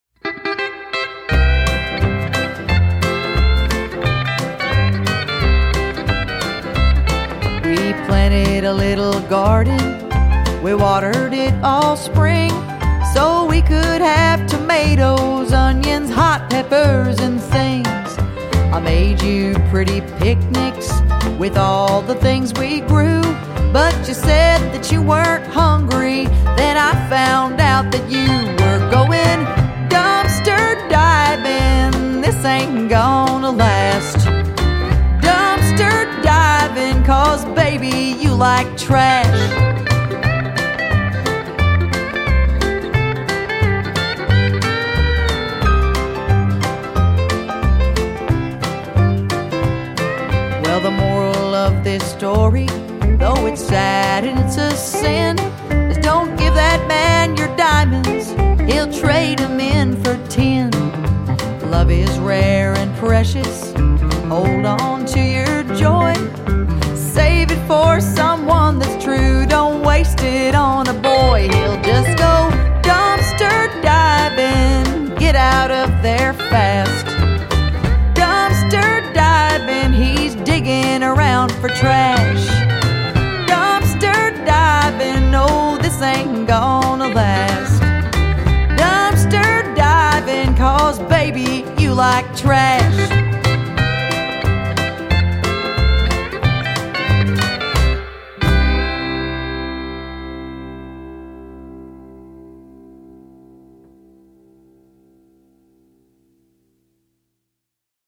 исполняющей классическую кантри-музыку
Чистый голос, мастерская игра на флейте.